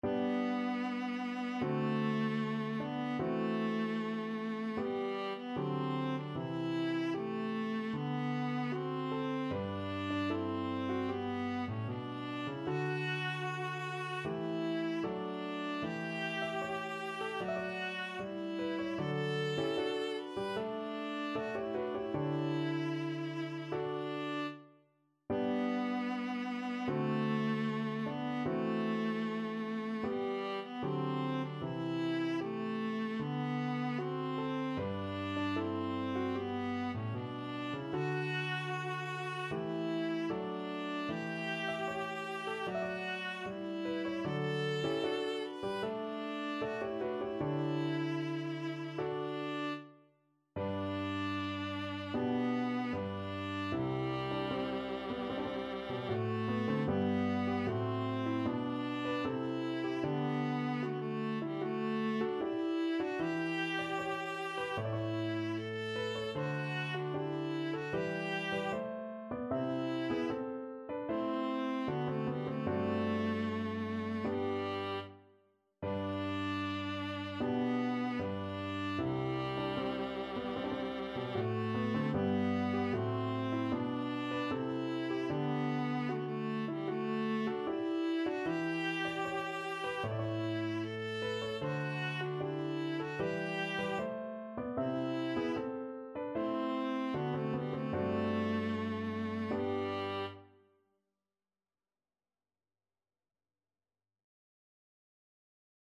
Viola version
Andante =76
4/4 (View more 4/4 Music)
Classical (View more Classical Viola Music)